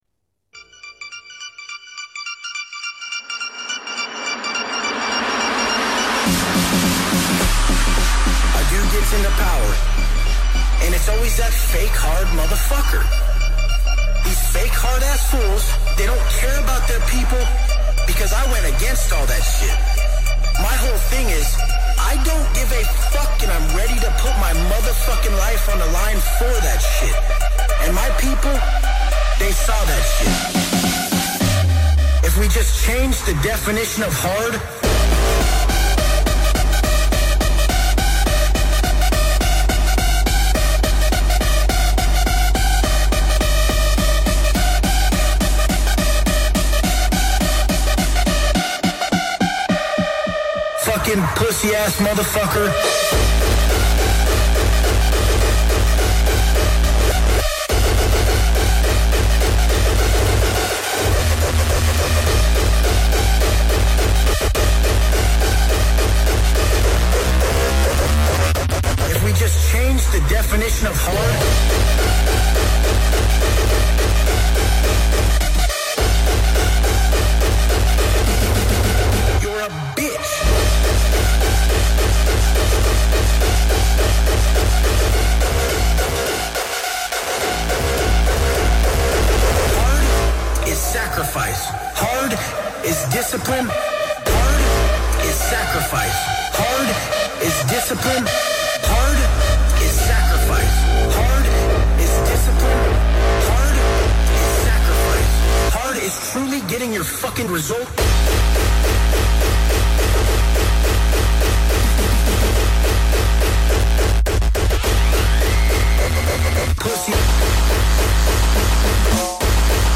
Genre: Hardcore